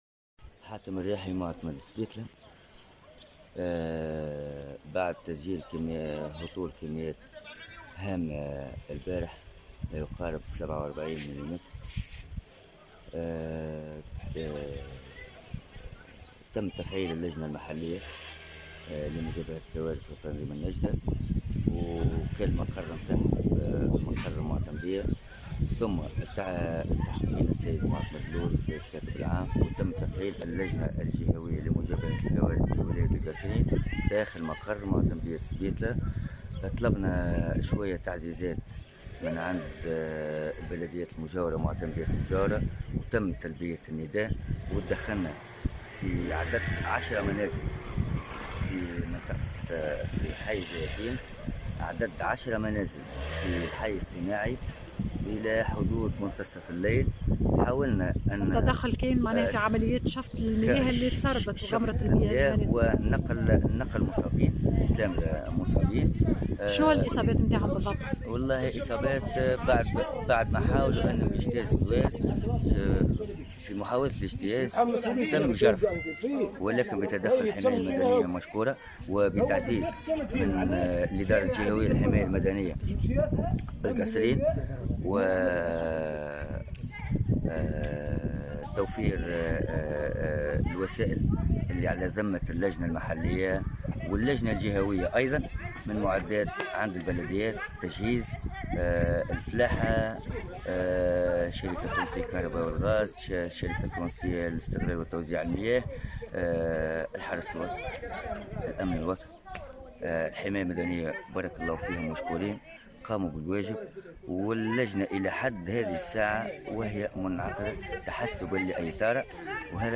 آخر تطورات الأوضاع في القصرين (تصريح+فيديو)